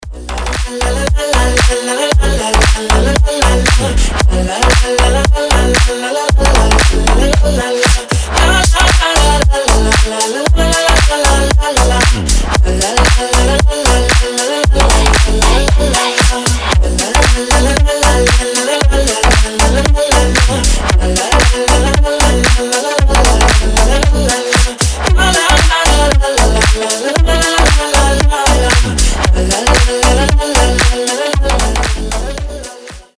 • Качество: 320, Stereo
громкие
веселые
EDM
мощные басы
future house
ремиксы
Та самая полька в клубной обработке